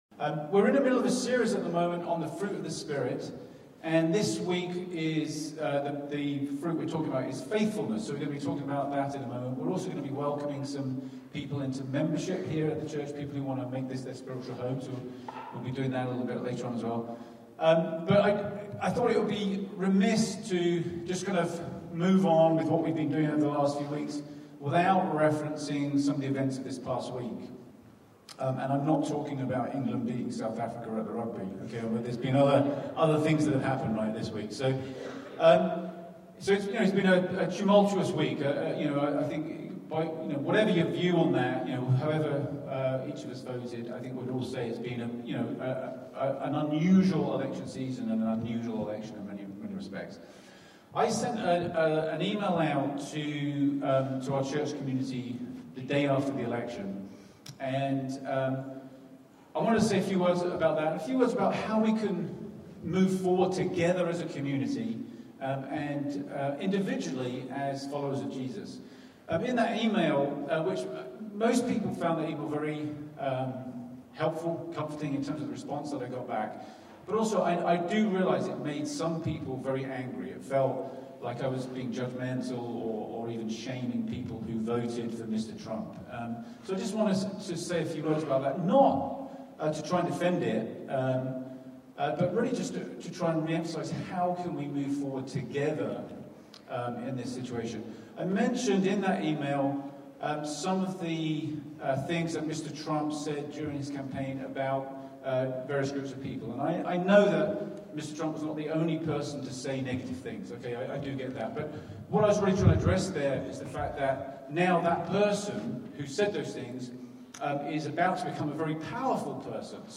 A message from the series "Reading Romans."